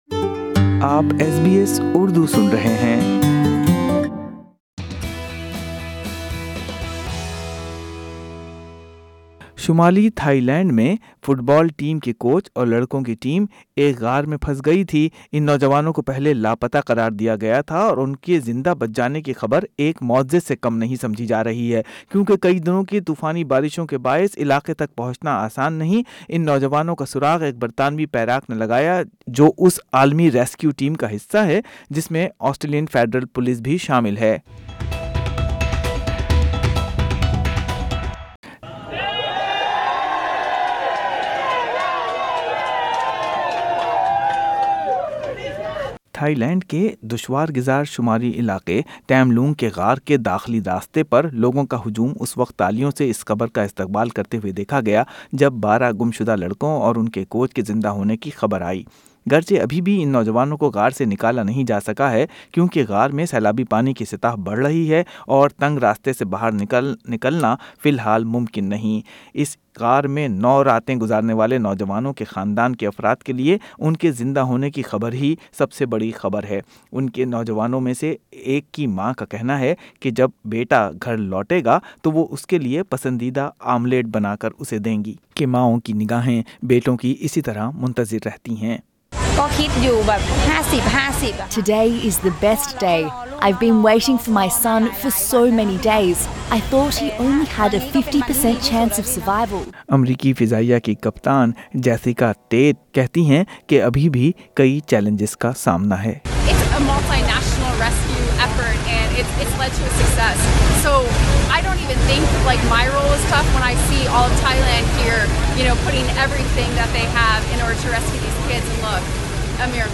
SBS Urdu View Podcast Series